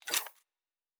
Weapon 11 Foley 3 (Rocket Launcher).wav